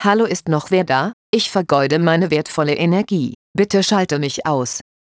hier mal eine mögliche Sprachmeldung für die neue Sonderfunktionalität "Inaktivitätsalarm" 5 / 10 / 15 Minuten oder ohne Angabe von Zeit